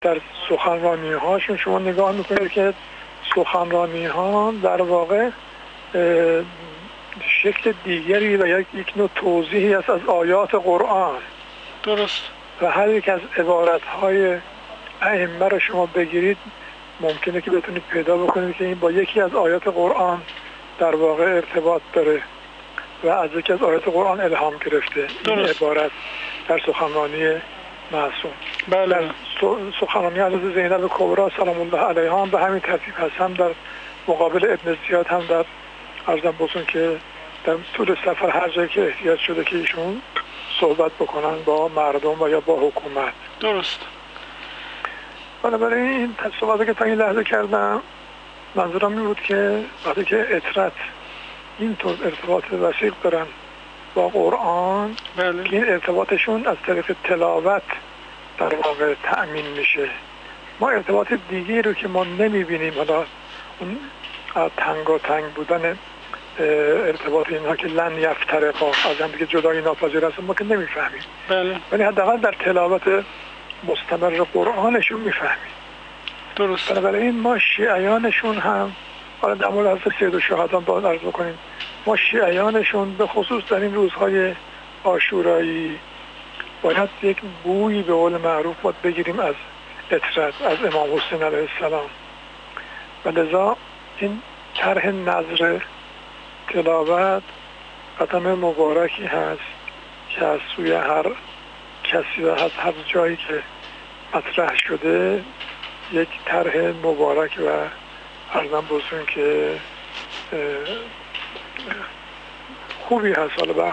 در گفت‌وگو با خبرنگار ایکنا